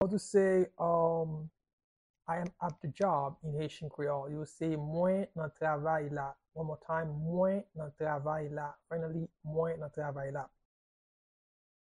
Pronunciation and Transcript:
How-to-say-I-am-at-the-job-in-Haitian-Creole-–-Mwen-nan-travay-la-pronunciation-by-a-Haitian-speaker.mp3